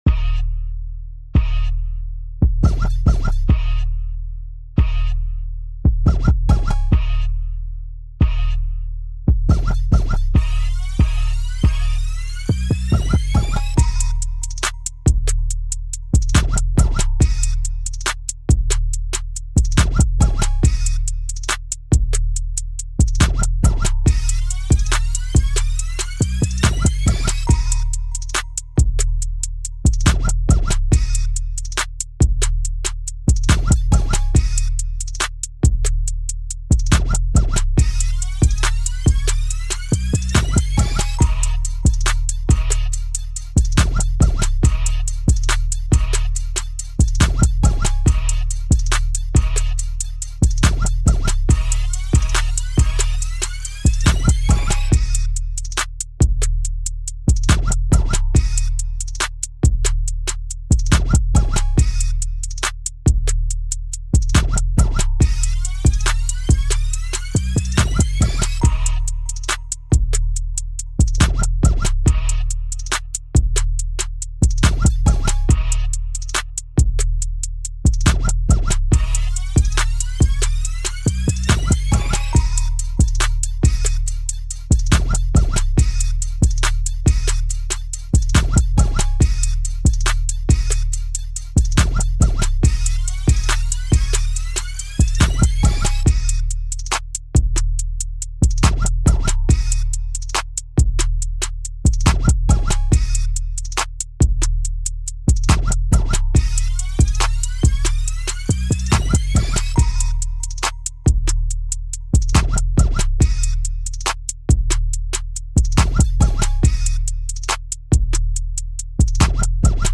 Hip Hop
EB Minor